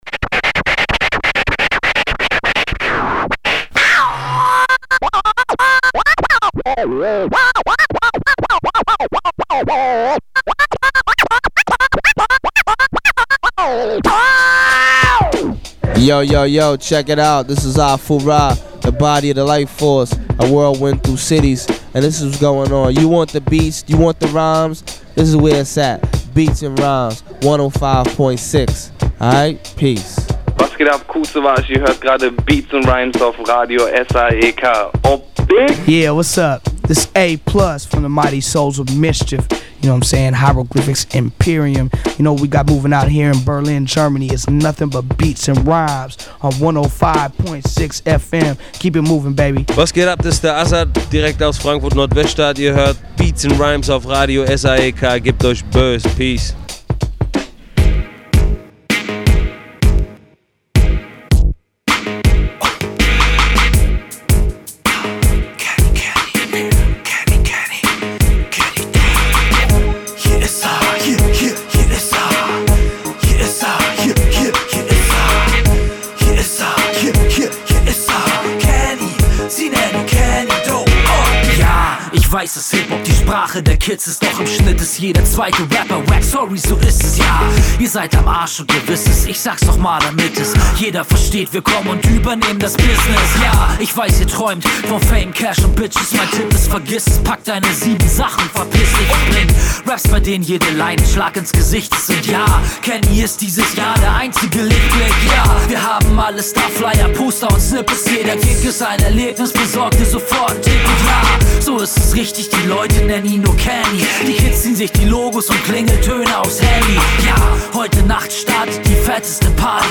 Inhalte: Newz, VA Tips, Musik